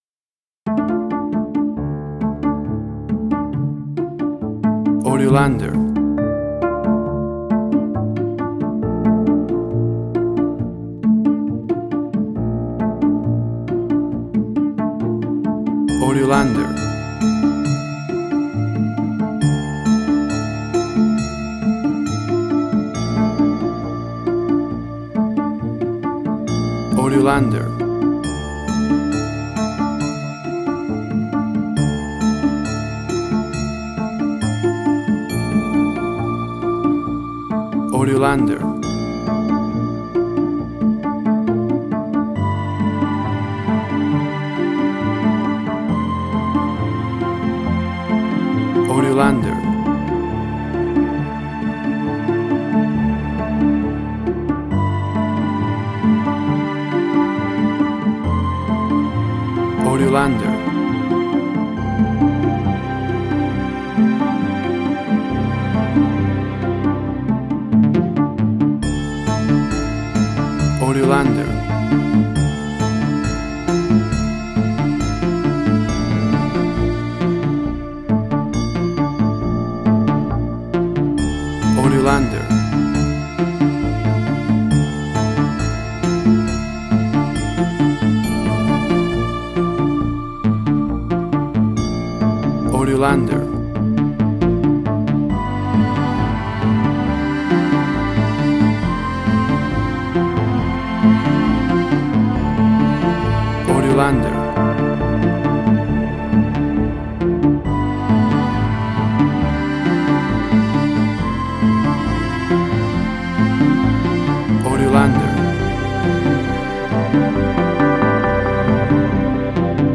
Suspense, Drama, Quirky, Emotional.
Tempo (BPM): 68